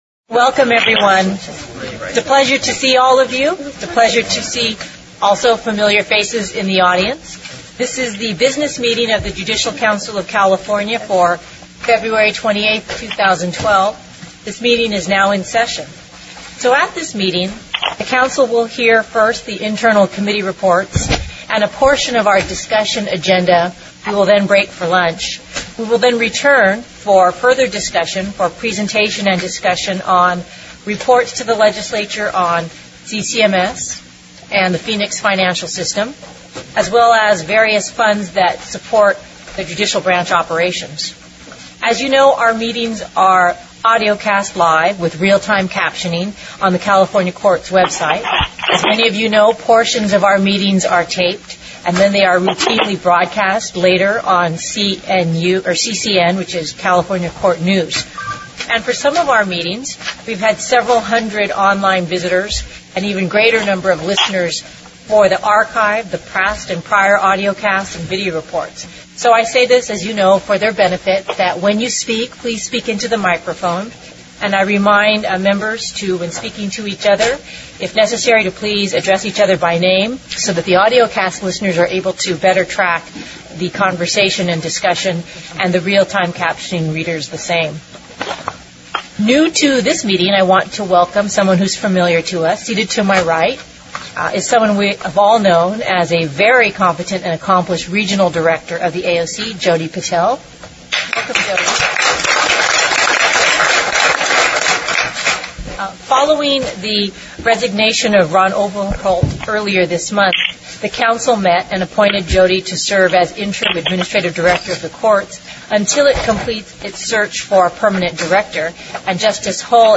Public Meeting Audio Archive (MP3)